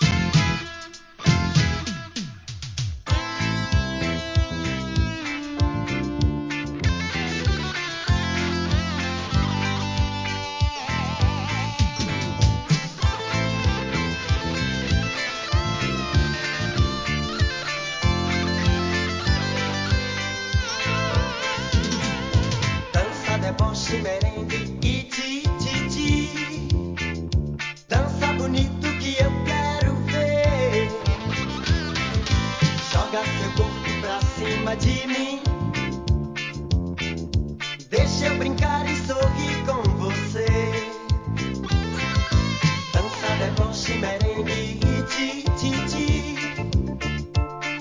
ブラジル産レゲエ